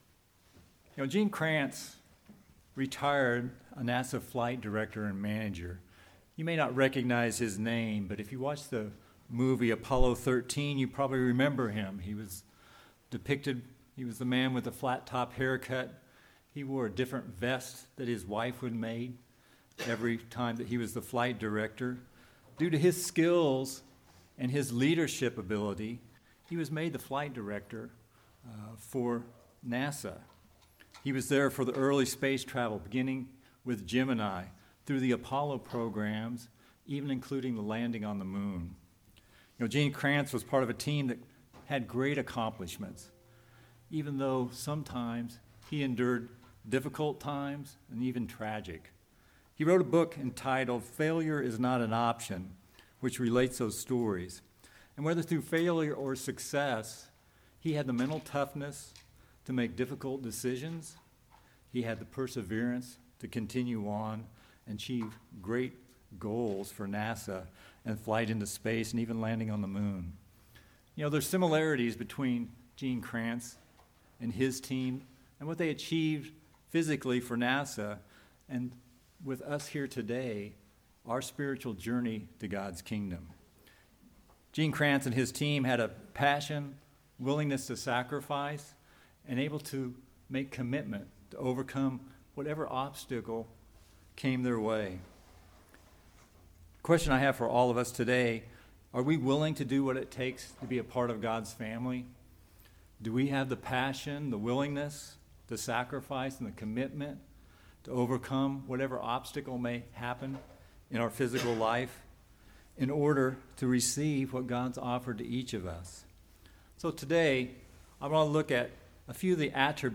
In this sermon we will discuss the parallels of what it will take for us to persevere and complete our journey to God’s kingdom. We will need those same attributes in order to receive what God has offered us!